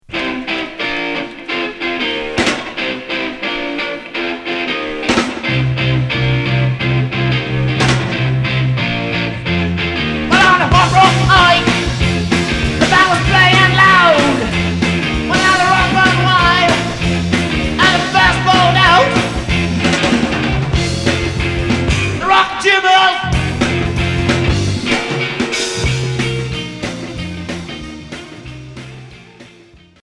Rock garage